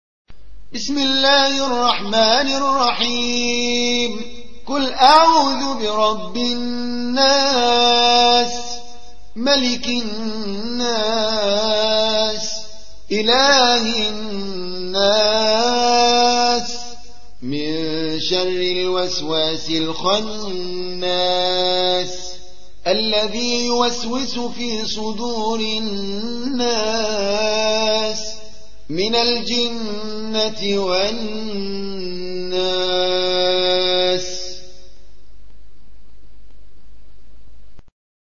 114. سورة الناس / القارئ